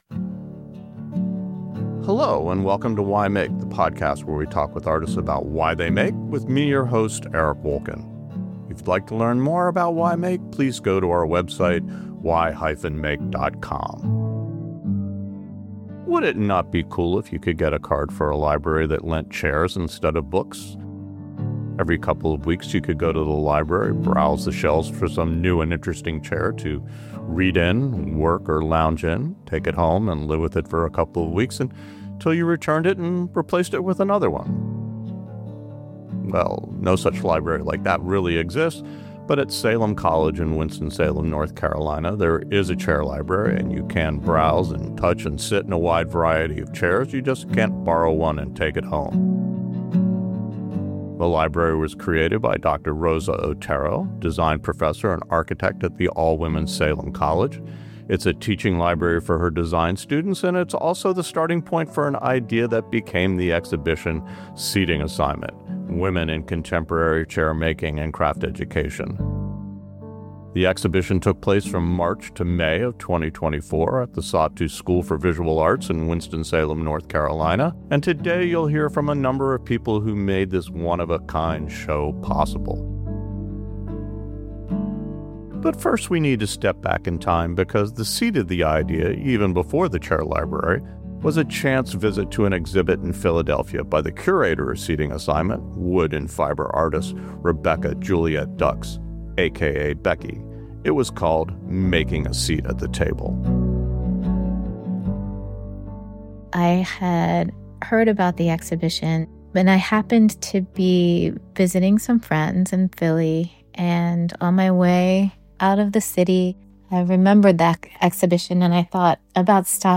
monthly conversations with makers exploring creativity and why they make